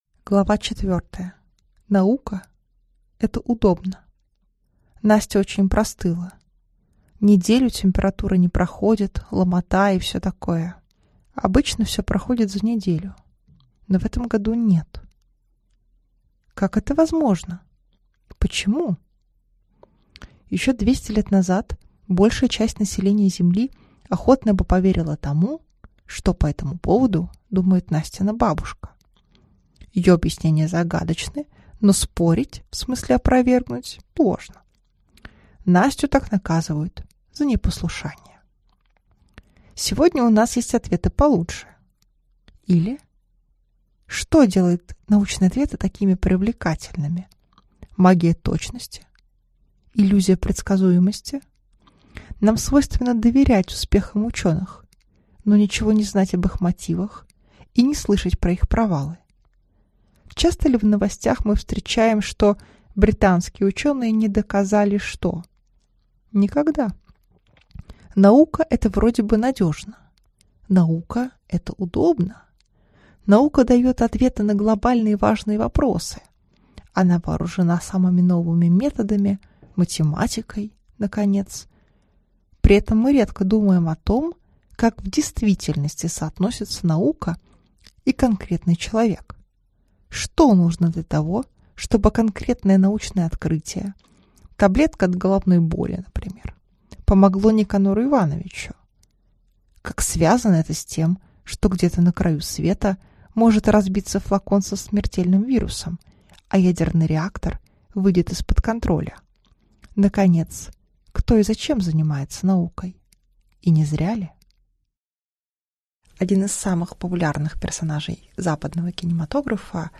Аудиокнига Наука – это удобно | Библиотека аудиокниг
Прослушать и бесплатно скачать фрагмент аудиокниги